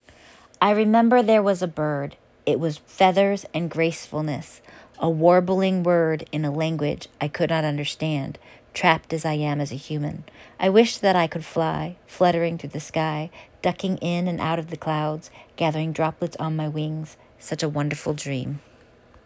Fantasy-spoken.m4a